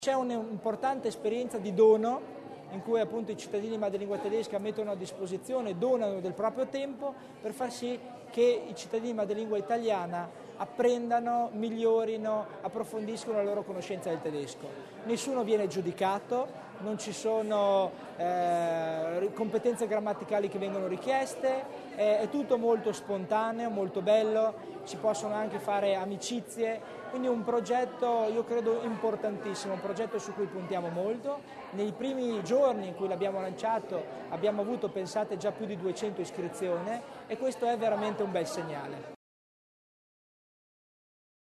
Il Vicepresidente Tommasini sull’importanza del progetto